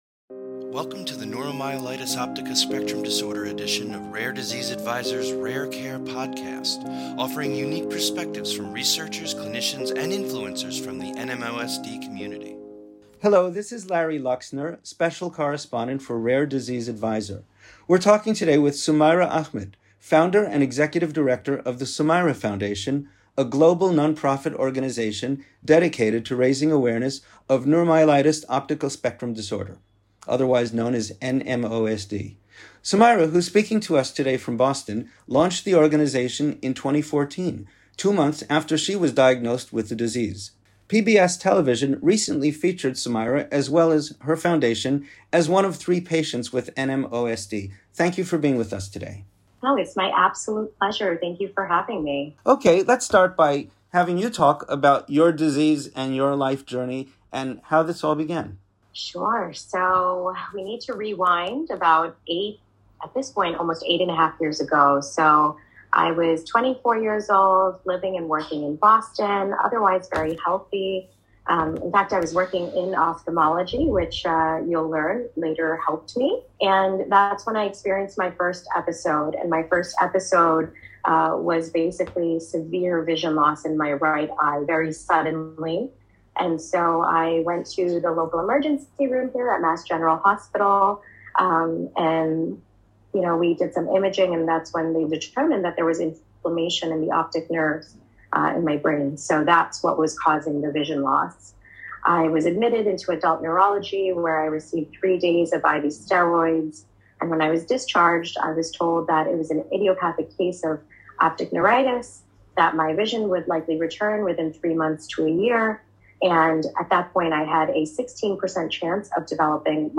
50: An Interview